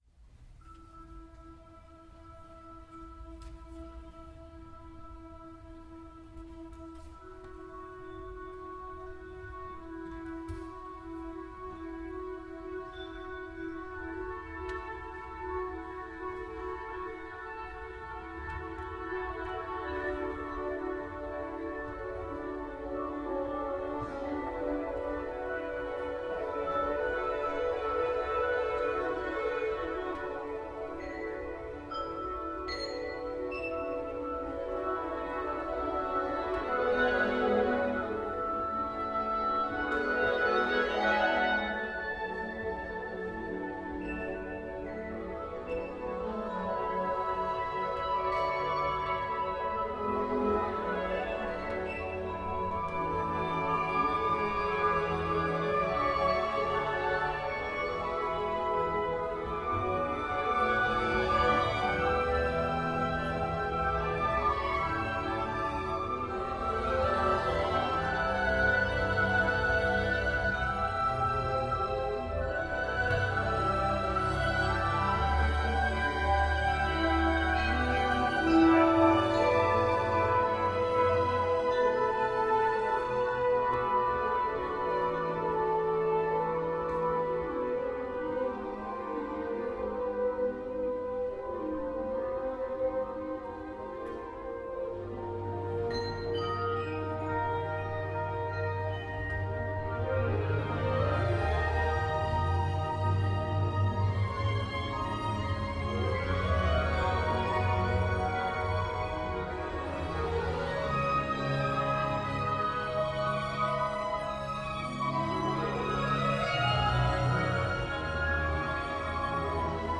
View the score Listen to a sample recording by the Stratford Symphony Orchestra
Reflection and Resolution A lush orchestral work with lyrical wind solos.
The melodies that float into the introduction are often presented in duets where the lower voice follows the upper in contrary motion – a literal musical reflection. Underneath the melodies, the harmonic progressions shift inwards and outwards in another kind of musical reflection. As they do, the lower chords clash deliciously with the upper chords.
After reflecting back on the introductory musical material the work resolves itself to move beyond and finish with a majestic, resolute flourish.